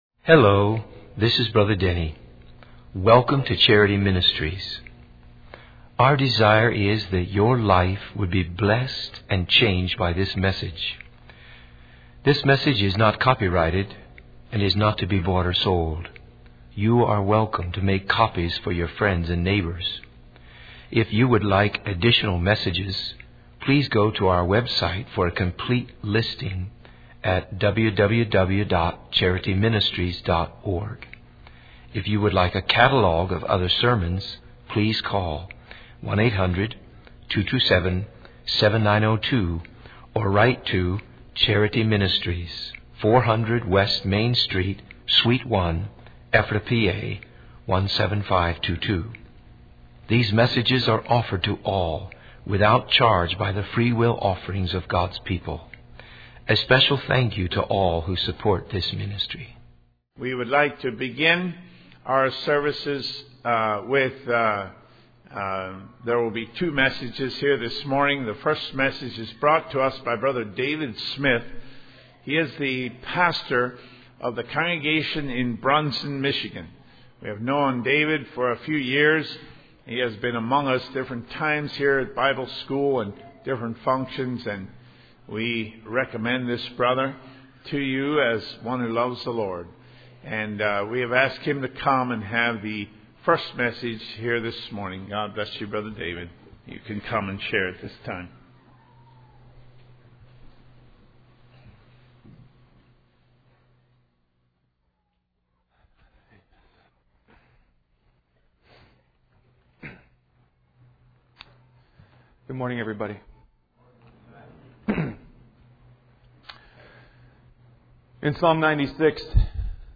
In this sermon, the speaker emphasizes the importance of living a life that brings glory to God. He encourages young people to move beyond seeking personal pleasure and instead focus on serving Christ. The sermon references John chapter 12, where certain Greeks express their desire to see Jesus.